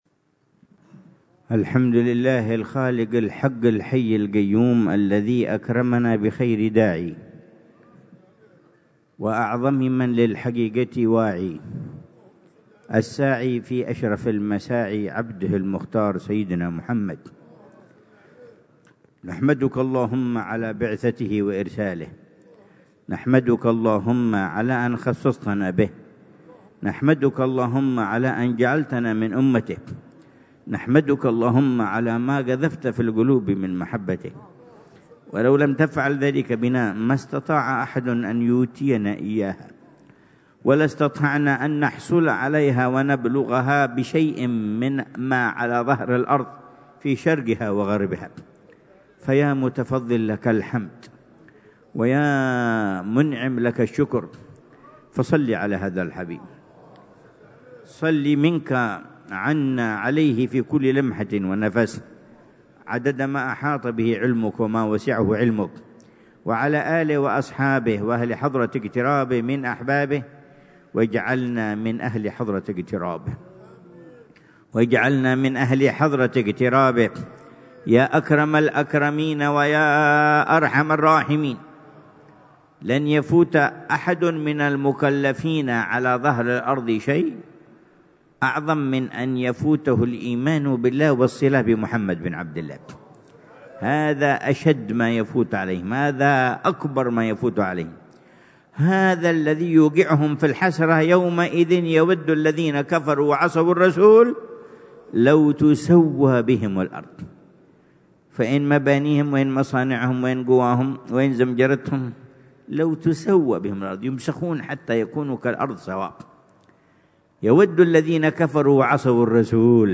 محاضرة العلامة الحبيب عمر بن محمد بن حفيظ ضمن سلسلة إرشادات السلوك في دار المصطفى، ليلة الجمعة 5 جمادى الآخرة 1446هـ، بعنوان: